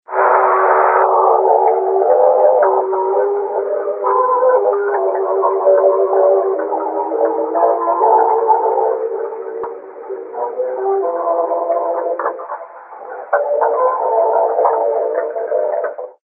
Then the music came on, from 3000 miles away: a dance band at the hotel, and a studio program featuring a violin and piano.
Music Sample 1 from the broadcast.